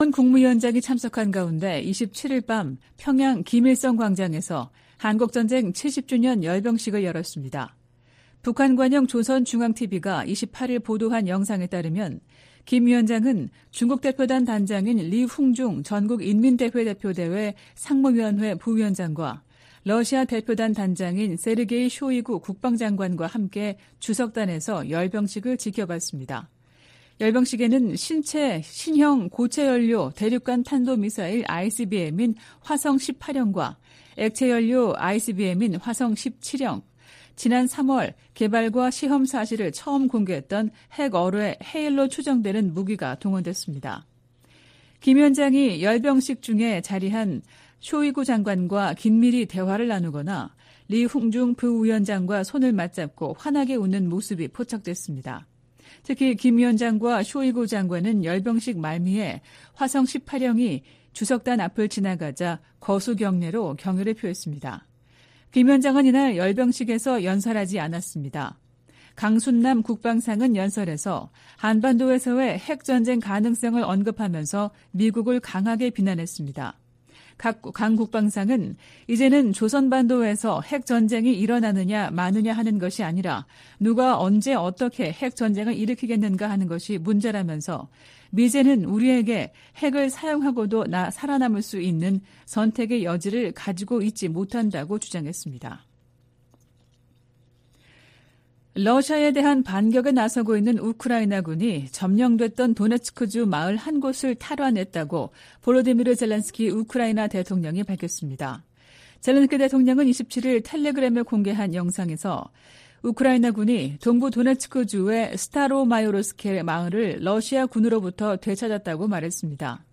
VOA 한국어 '출발 뉴스 쇼', 2023년 7월 29일 방송입니다. 미 국무부는 러시아가 북한의 불법 무기 프로그램을 지원하고 있다고 비난했습니다. 로이드 오스틴 미 국방장관은 미한 상호방위조약이 체결된 지 70년이 지난 지금 동맹은 어느 때보다 강력하다고 밝혔습니다. 북한은 김정은 국무위원장이 참석한 가운데 '전승절' 열병식을 열고 대륙간탄도미사일 등 핵 무력을 과시했습니다.